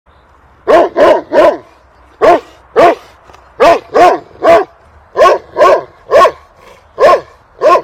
03-kutya.mp3